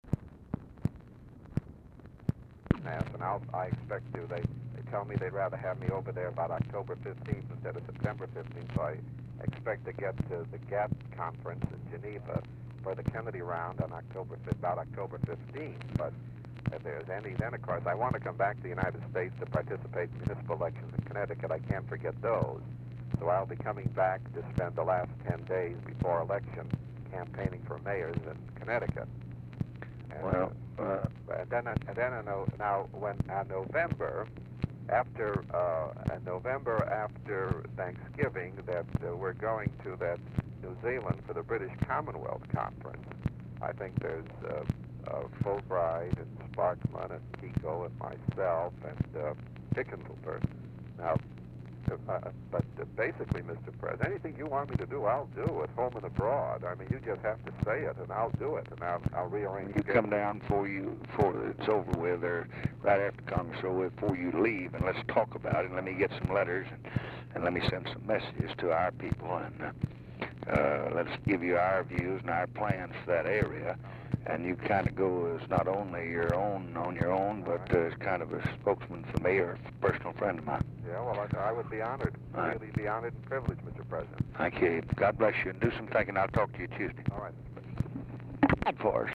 Telephone conversation # 8802, sound recording, LBJ and ABRAHAM RIBICOFF, 9/1/1965, 8:45AM | Discover LBJ
Format Dictation belt
Location Of Speaker 1 Mansion, White House, Washington, DC
Specific Item Type Telephone conversation